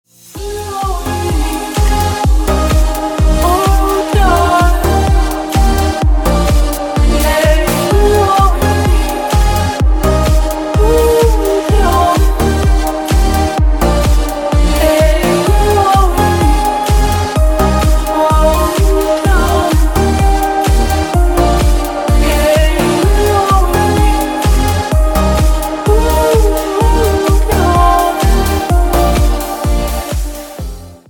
• Качество: 160, Stereo
ритмичные
женский вокал
спокойные
романтичные
progressive trance